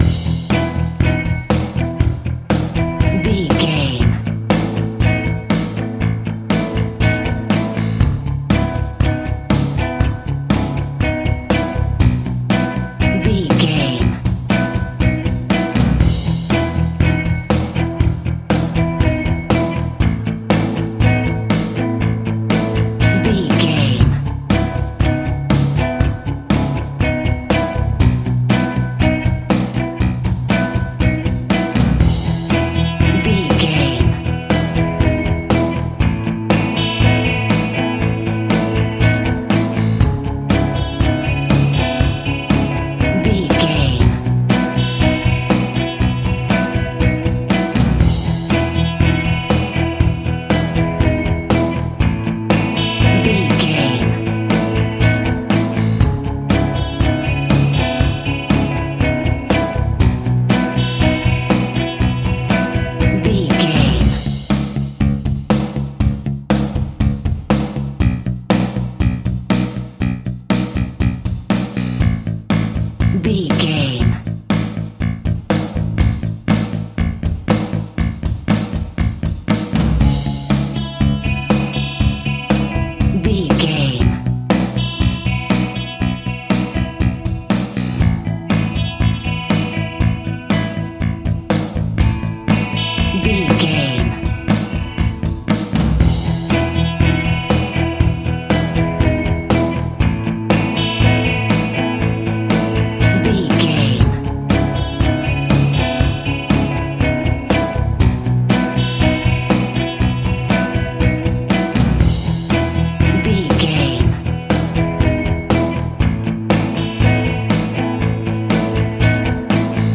Reality TV Pop Rock Music Cue.
Ionian/Major
melancholic
happy
energetic
positive
smooth
soft
uplifting
electric guitar
bass guitar
drums
indie pop
synthesizers